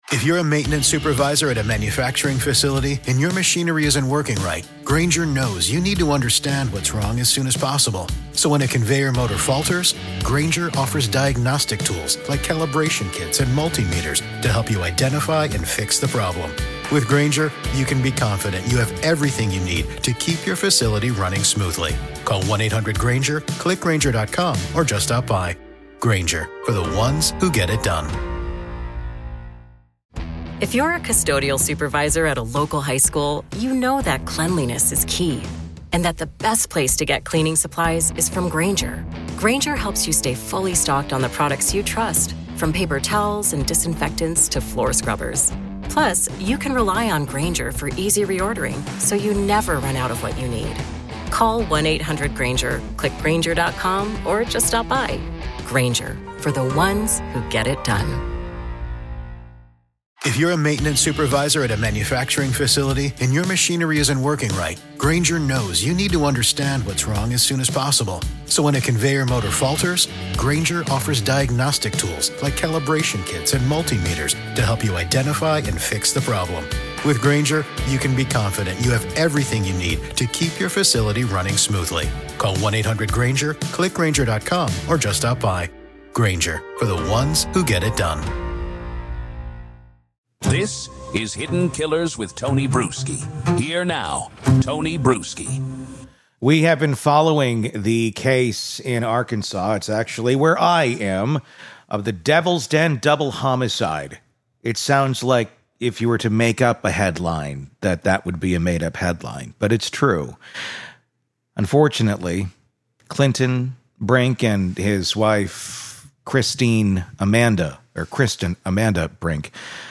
multi-guest episode
featuring exclusive interviews with three critical voices